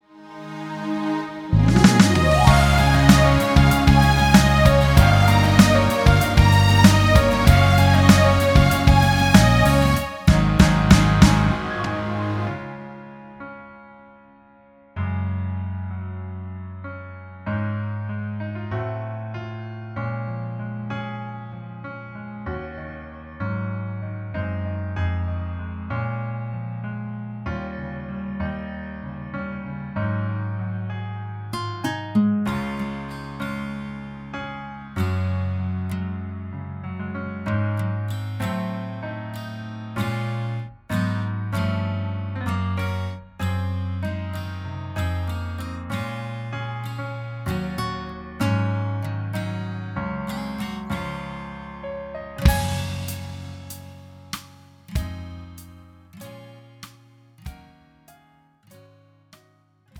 음정 -1키 4:22
장르 가요 구분 Pro MR